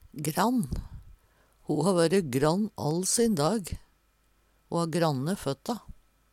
grann - Numedalsmål (en-US)